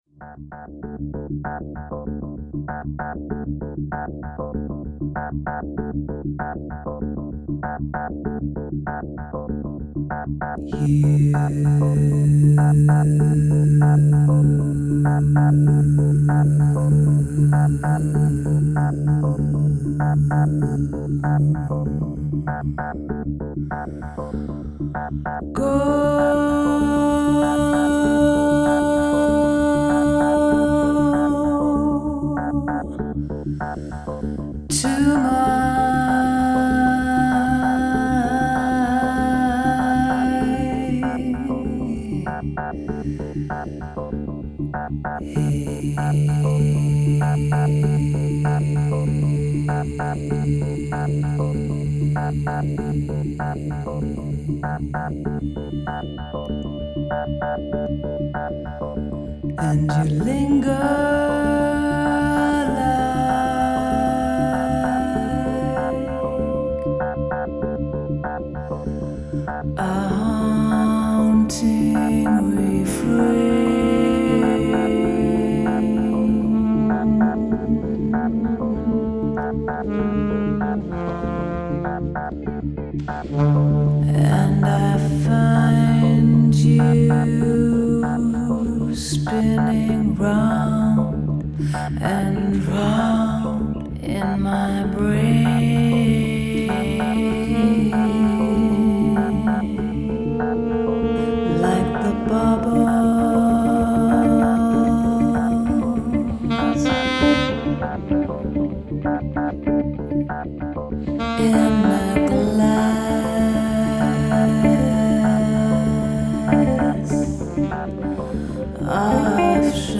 Altsaxofon